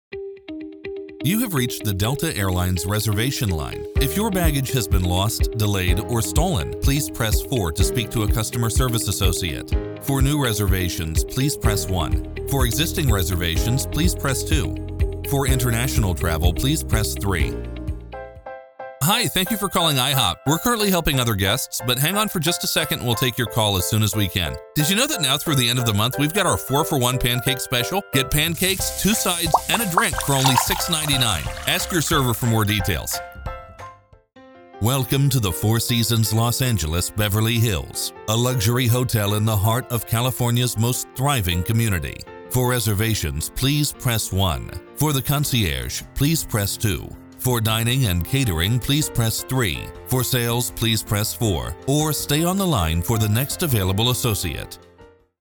standard us
phone message